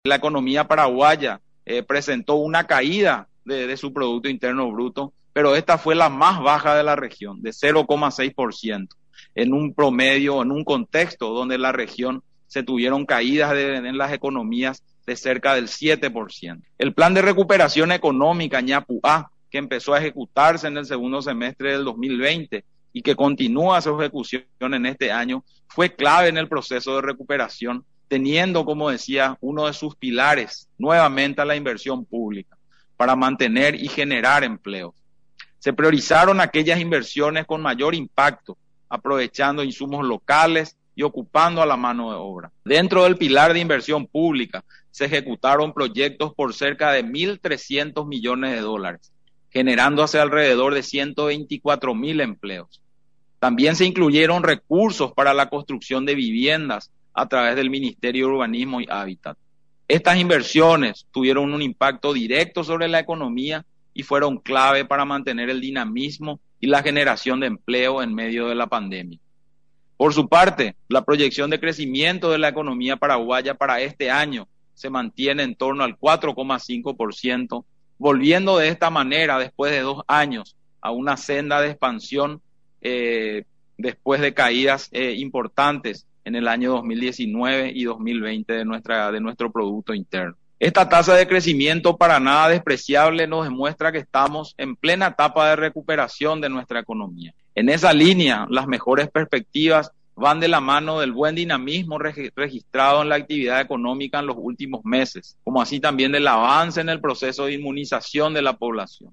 En conversación en Radio Nacional, recordó que desde la cuarentena por la pandemia del coronavirus, en nuestro país solo descendió un 0,6% en relación al 7% de los demás países de la zona.